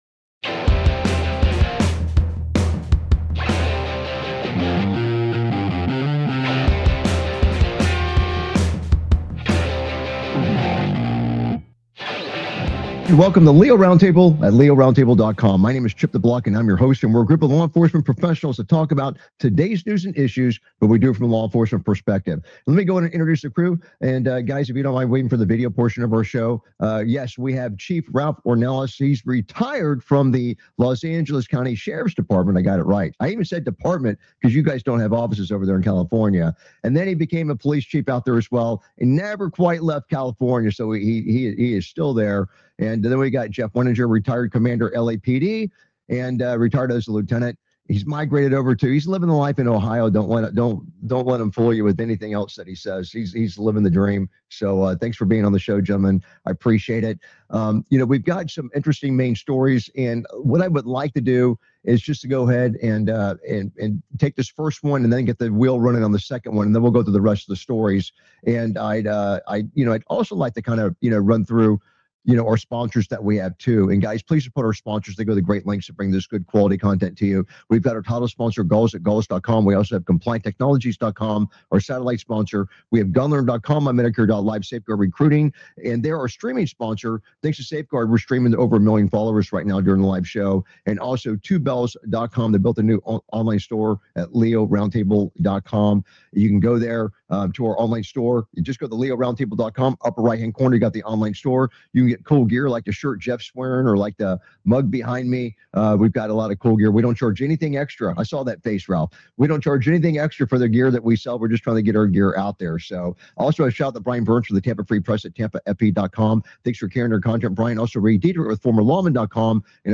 Talk Show Episode, Audio Podcast, LEO Round Table and S11E048, Sen.